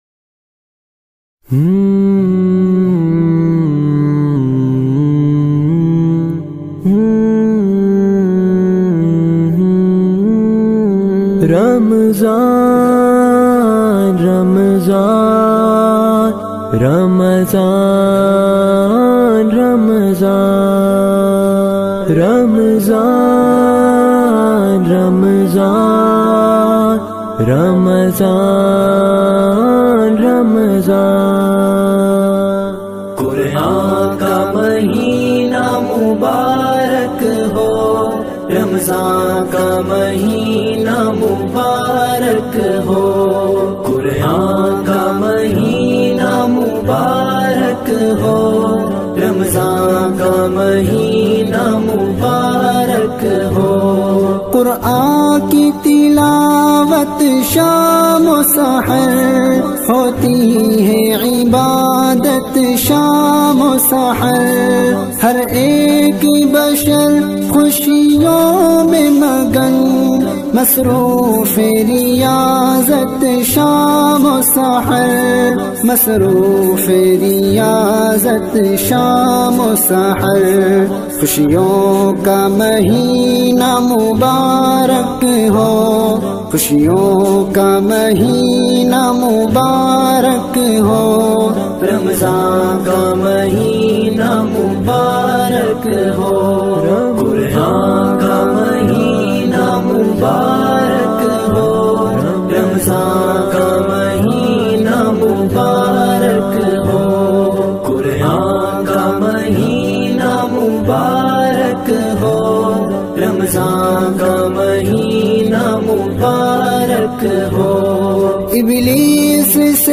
in a Heart-Touching Voice
His amazing accent draws in his followers.